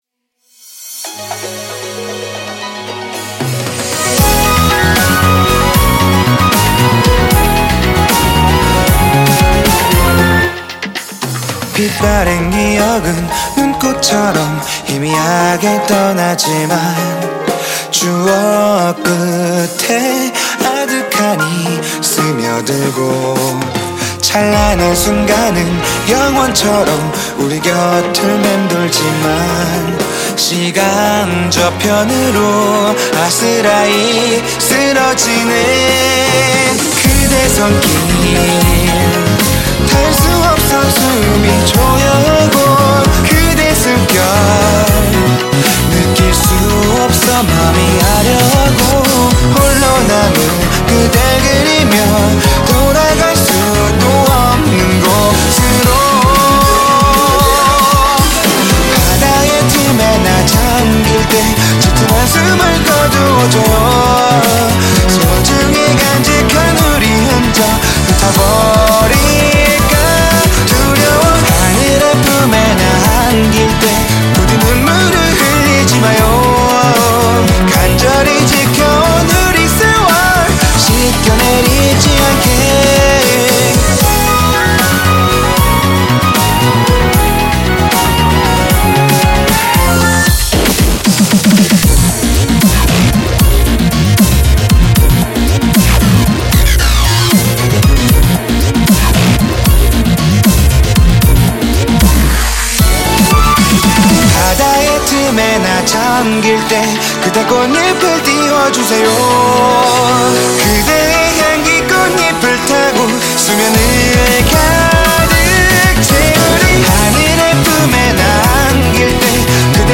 BPM77
Audio QualityPerfect (Low Quality)
You all ready for some...uh, dubthnic?